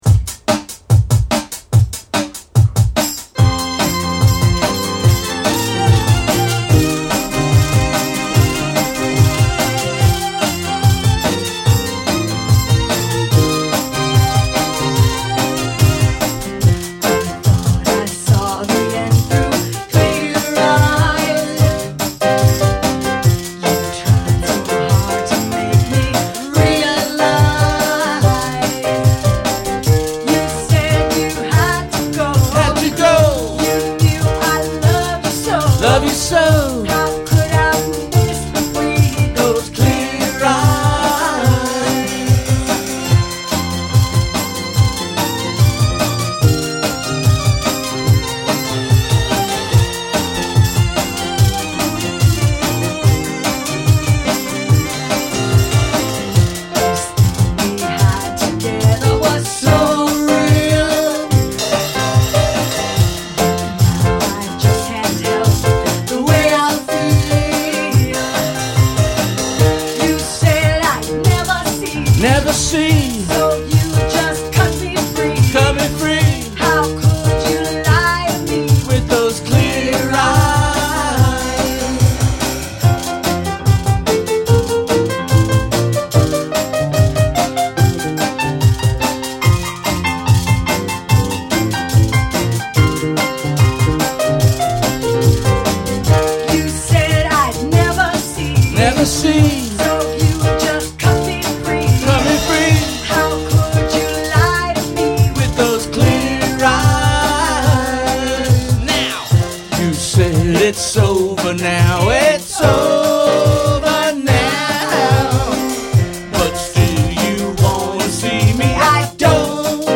Bass and Guitar
Congas, percussion
Vocals
Keyboards, vocals